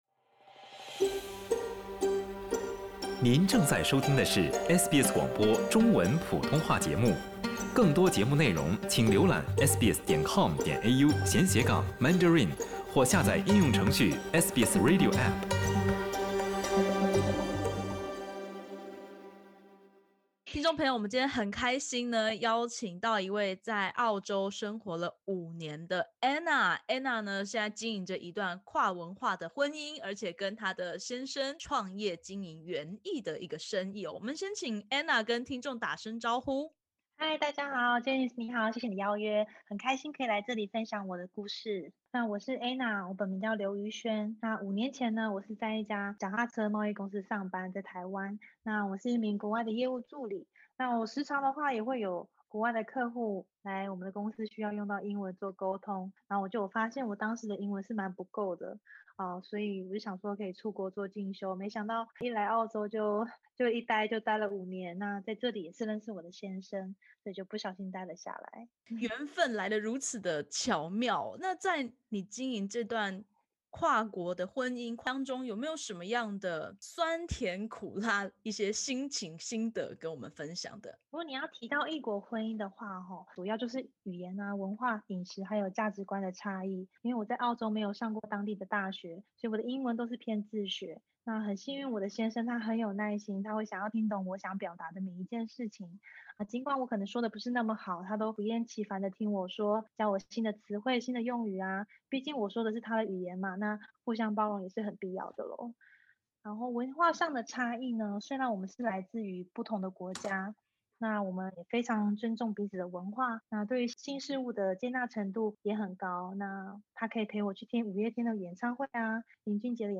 点击首图收听完整采访音频。